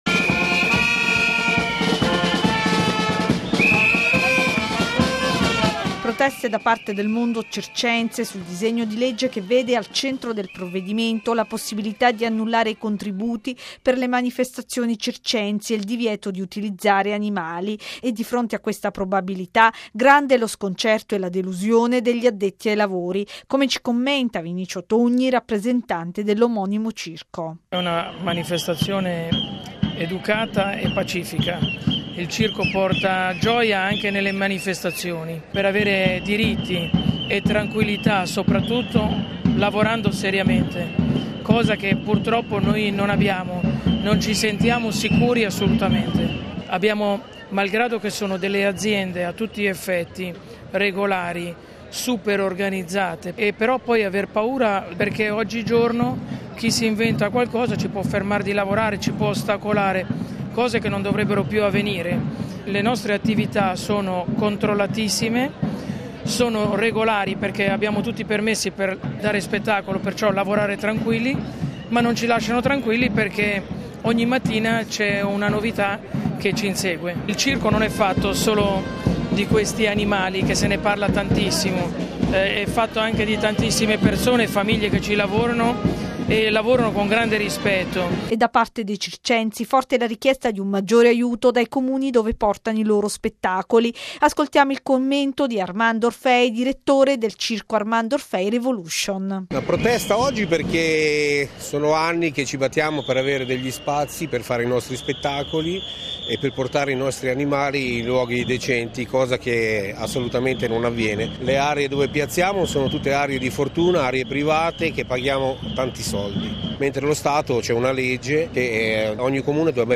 Questa mattina, in piazza della Rotonda a Roma si è svolta la protesta degli artisti circensi italiani contro il Disegno di legge che prevede l'eliminazione degli  animali dai circhi, in discussione in questi giorni al Senato.
Il servizio